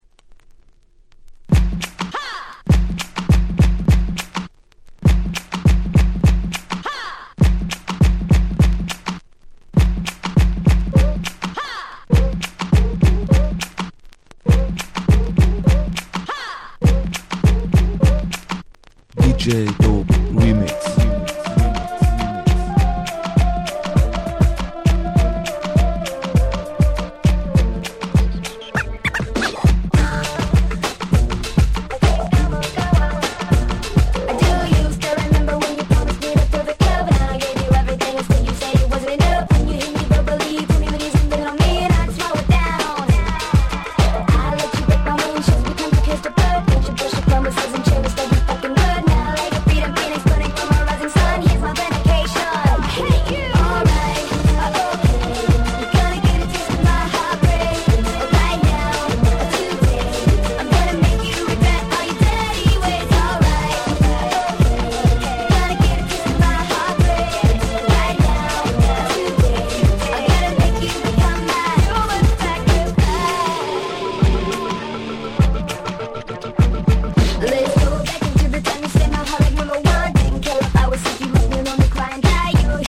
全6曲全部アゲアゲ！！